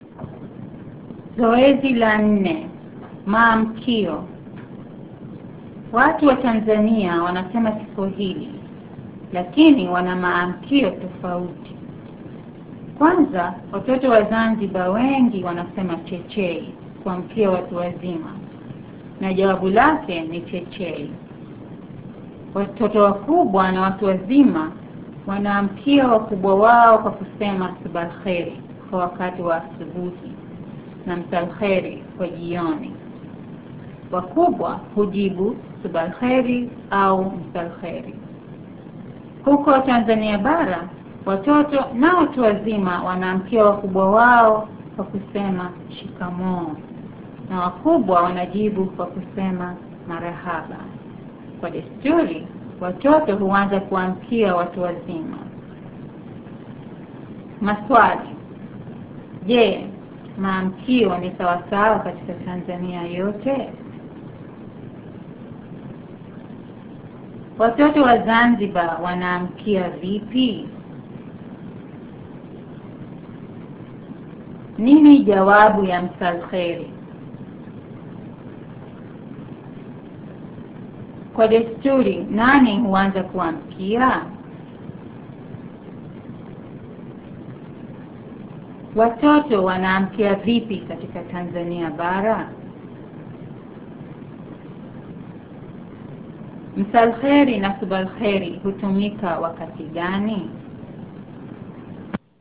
(Click here to hear reading) Maamkio Watu wa Tanzania Kiswahili lakini wana tofauti.